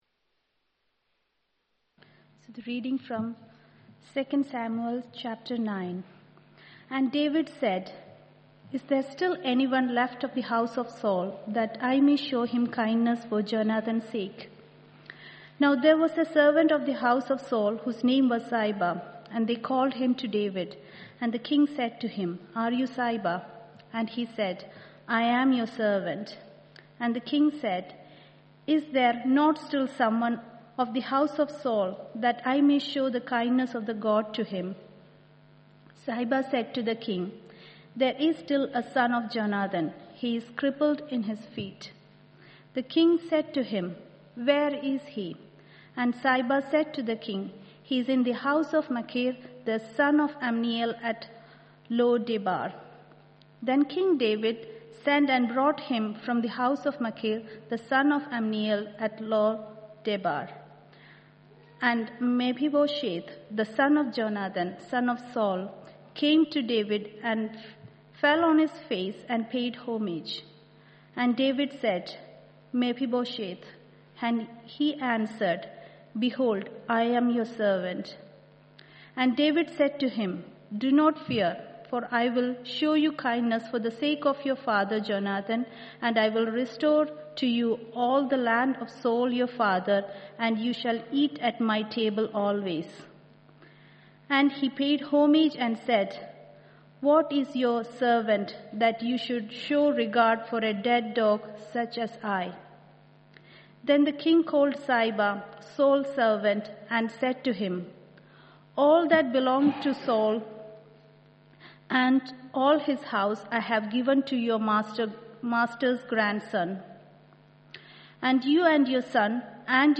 Communion Thought from 5 October